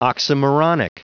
Prononciation du mot oxymoronic en anglais (fichier audio)
Prononciation du mot : oxymoronic